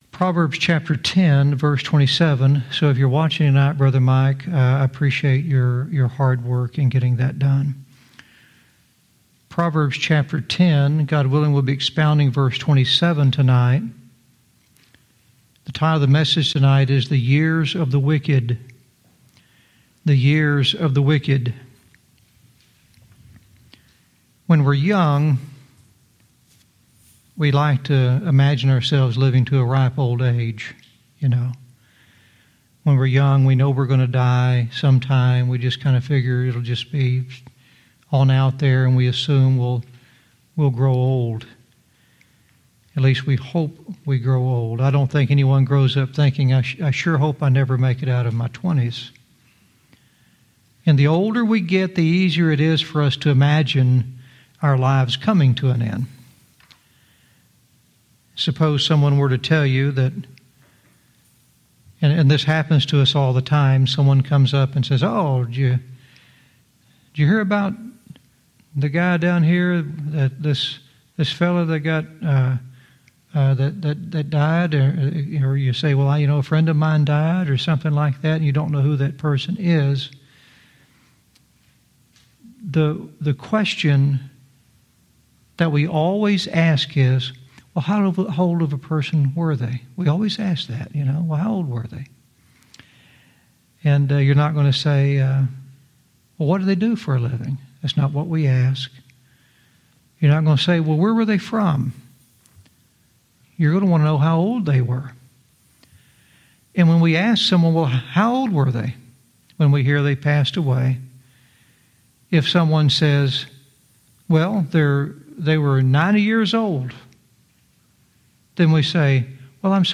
Verse by verse teaching - Proverbs 10:27 "The Years of the Wicked"